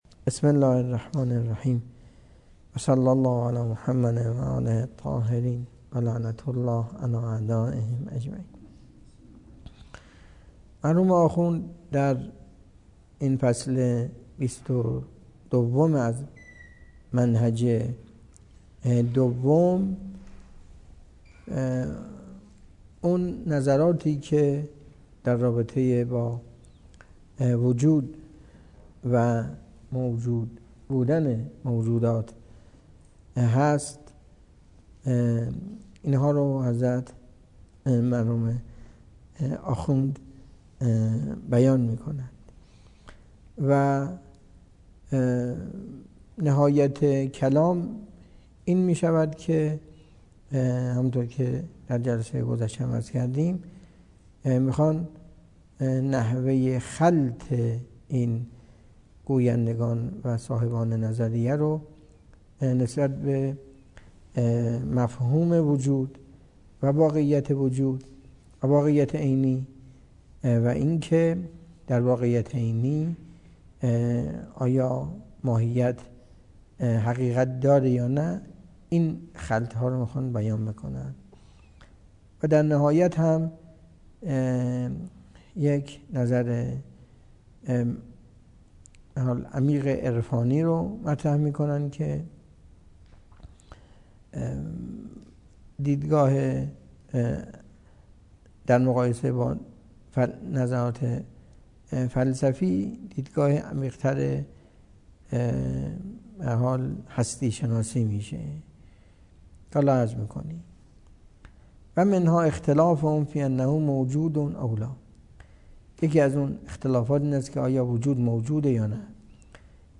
درس فلسفه اسفار اربعه
سخنرانی